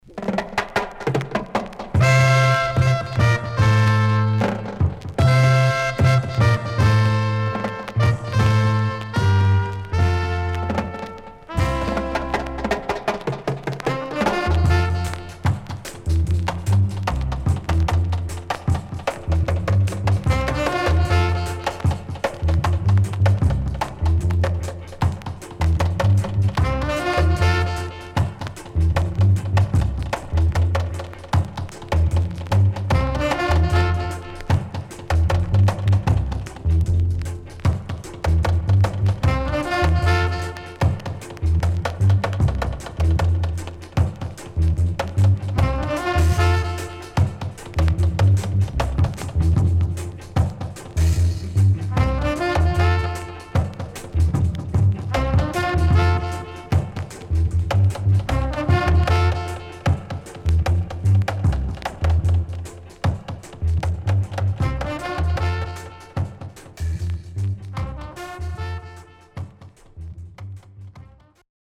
CONDITION SIDE A:VG+
riddim
SIDE A:少しチリノイズ入ります。